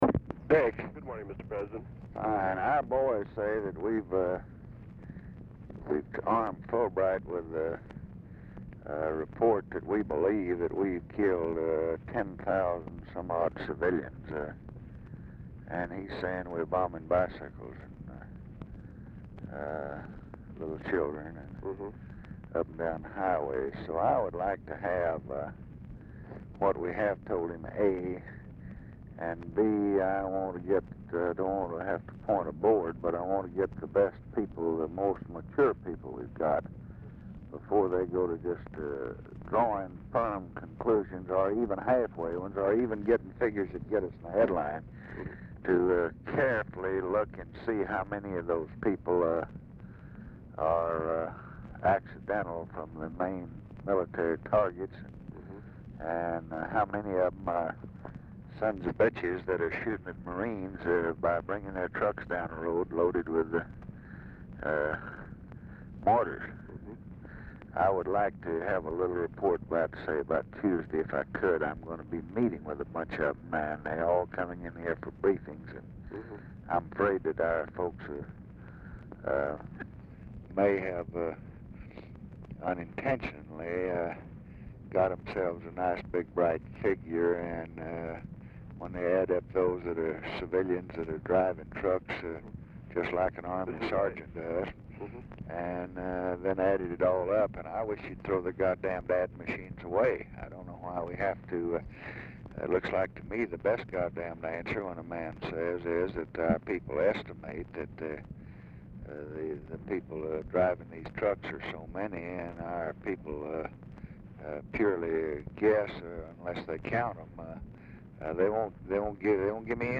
Telephone conversation # 11353, sound recording, LBJ and RICHARD HELMS, 1/14/1967, 10:55AM | Discover LBJ
Format Dictation belt
Location Of Speaker 1 Mansion, White House, Washington, DC
Specific Item Type Telephone conversation Subject Congressional Relations Defense Vietnam Vietnam Criticism